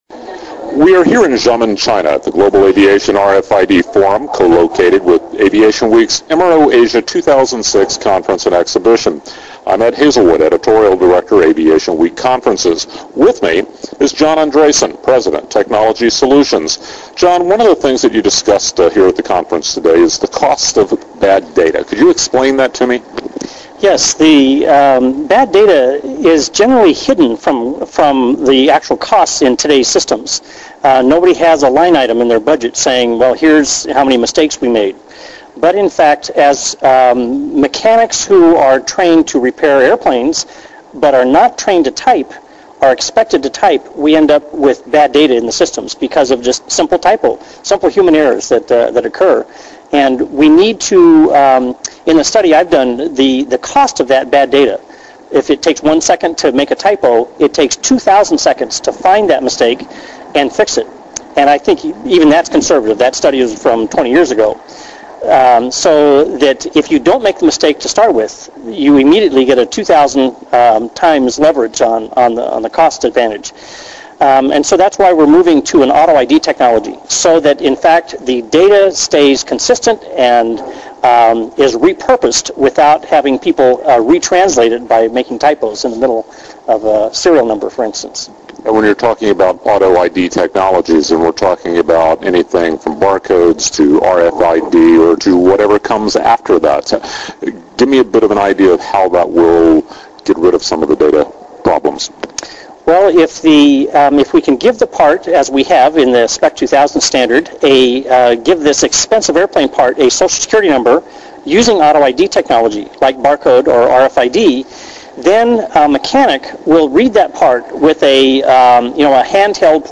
In this interview, industry experts discuss how poor data hygiene affects RFID accuracy and decision-making.
Cost-of-Bad-Data-Interview.m4a